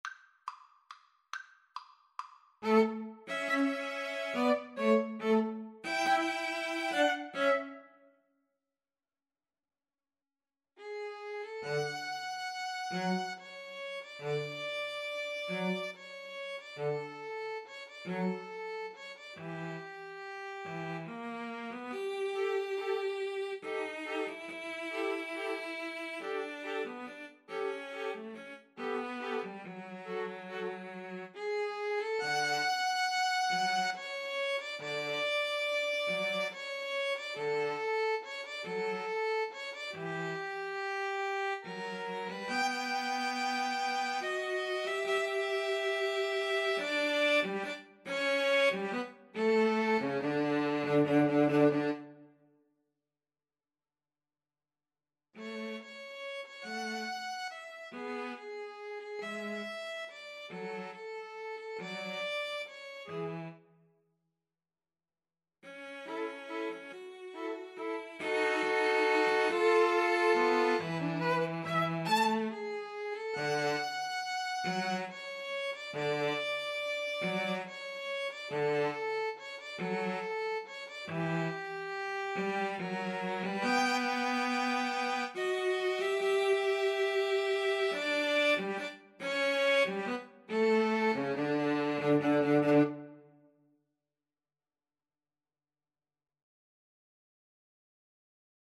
~ = 140 Tempo di Valse
3/4 (View more 3/4 Music)
Piano Trio  (View more Intermediate Piano Trio Music)